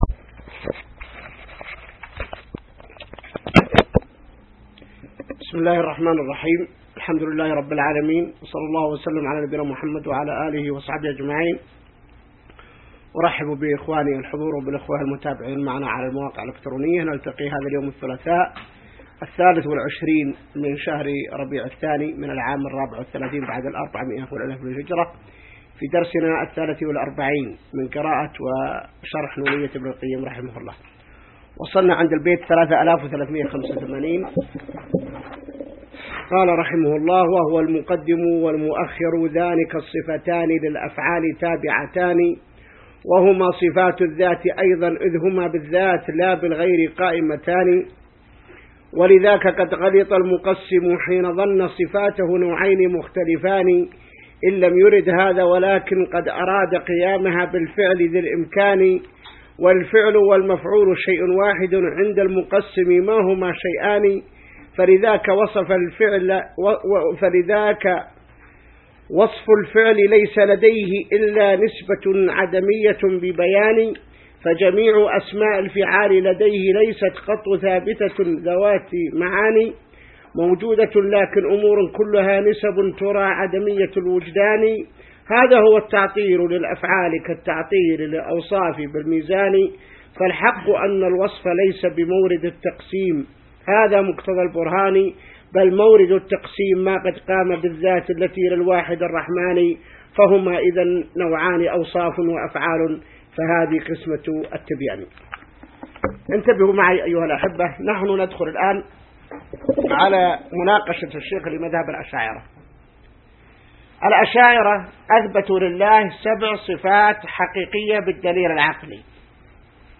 الدرس 43 من شرح نونية ابن القيم | موقع المسلم